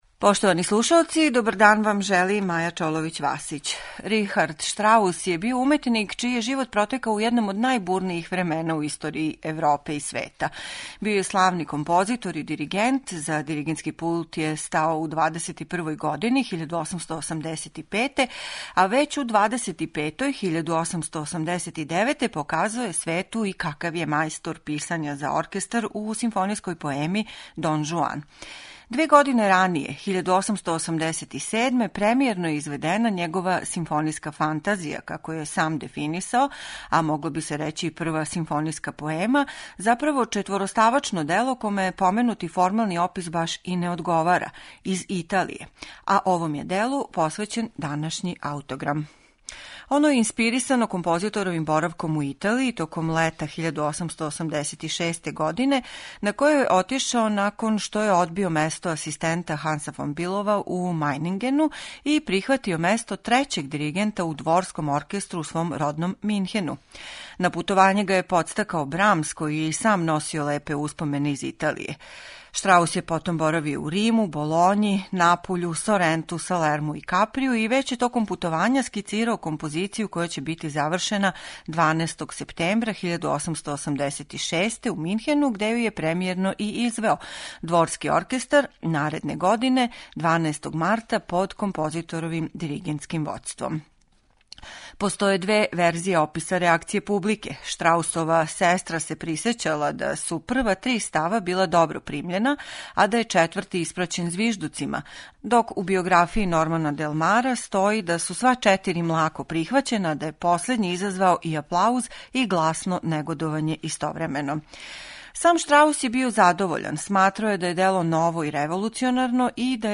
Дело које се сматра и његовом првом симфонијском поемом, иако је реч о четвороставачној композицији, доноси утиске са путовања по Италији током лета 1886.
Четири става композиције насловљени су: На селу, У рушевинам Рима, На плажи у Соренту и Сцене из напуљског живота. Слушаћете их у извођењу Оркестра Државне капеле у Дрездену, којим диригује Рудолф Кемпе.